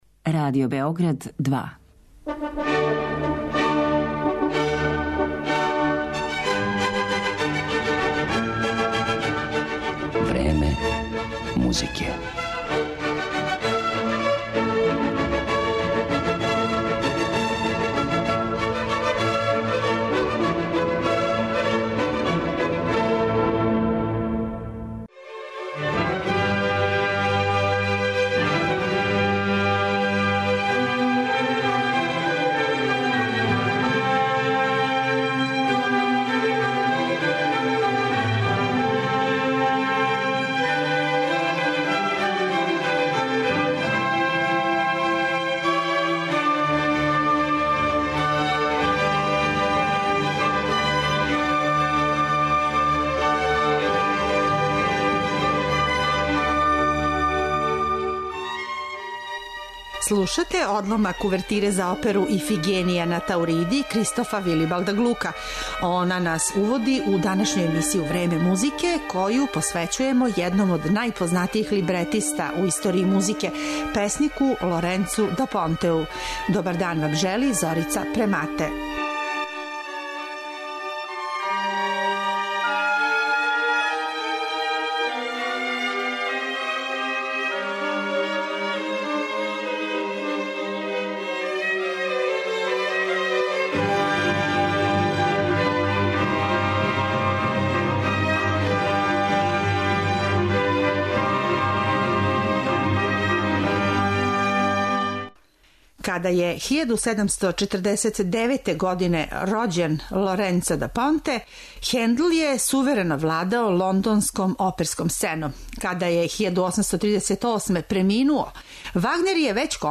Данас ћете његове стихове слушати у аријама из опера које су компоновали Антонио Салијери и Волфганг Амадеус Моцарт.